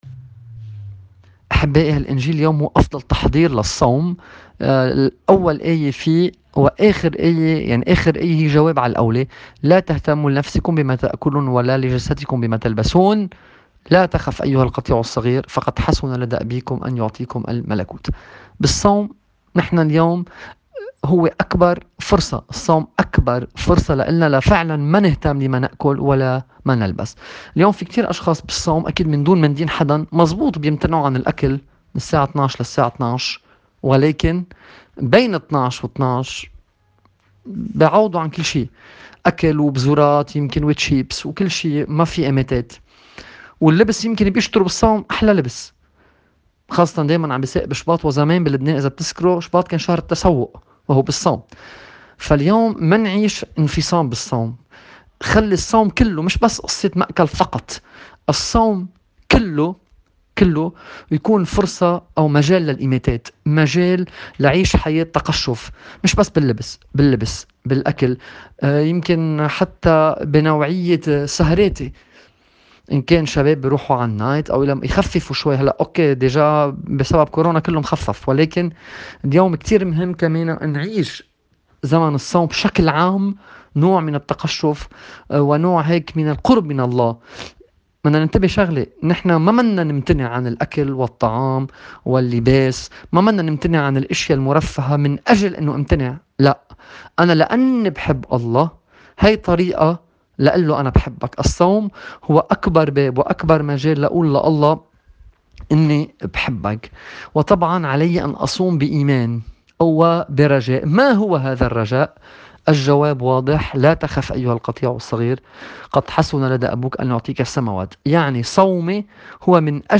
تأمّل في إنجيل اليوم